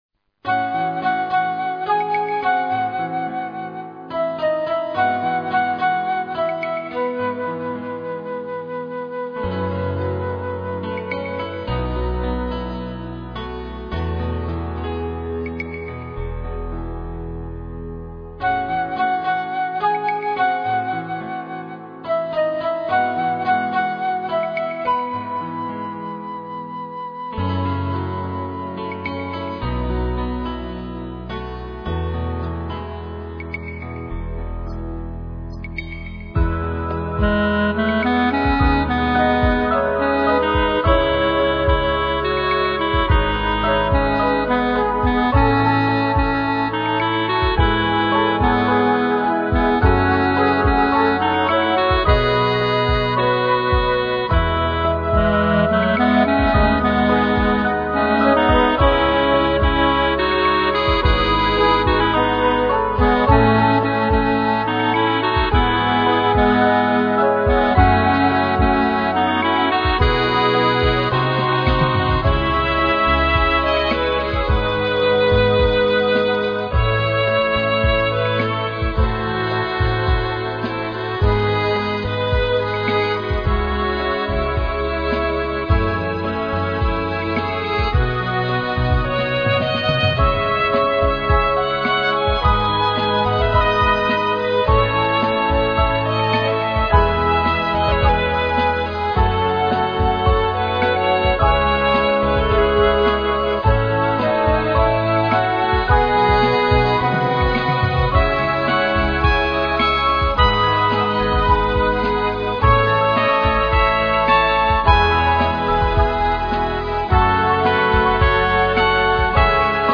アンサンブル曲